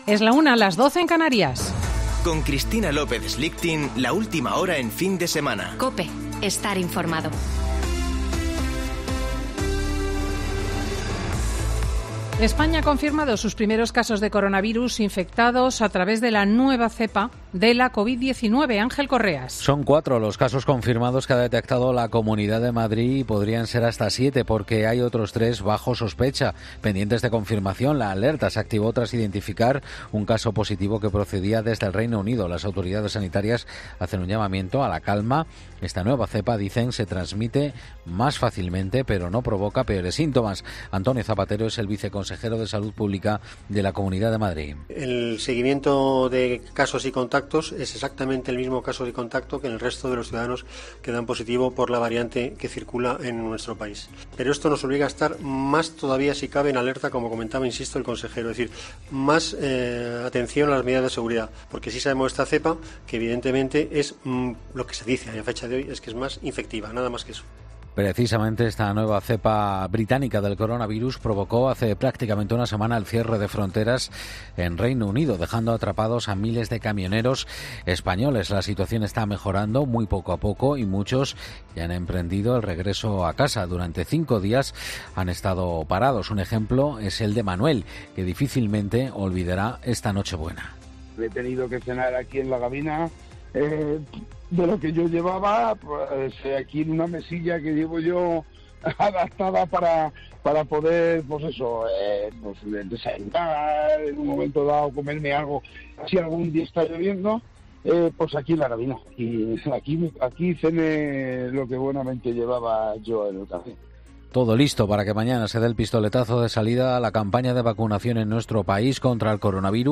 Boletín de noticias de COPE del 26 de diciembre de 2020 a las 13.00 horas